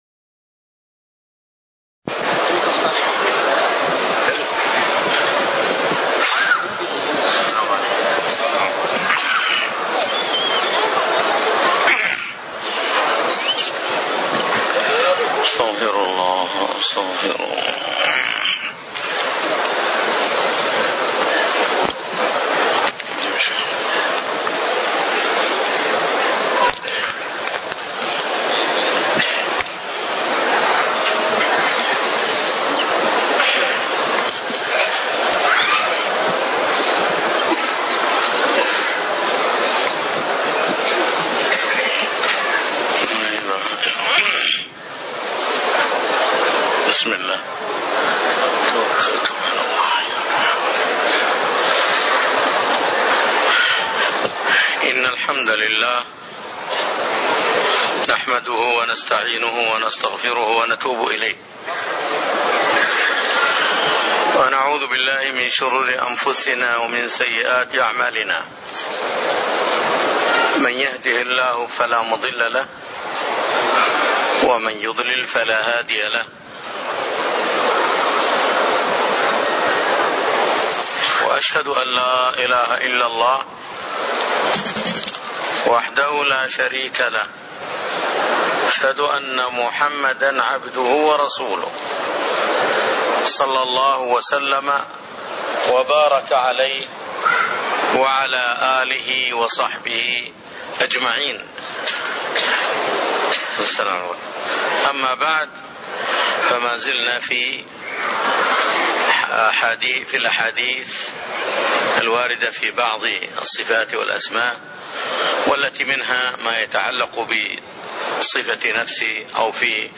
تاريخ النشر ٢٥ ربيع الثاني ١٤٣٣ هـ المكان: المسجد النبوي الشيخ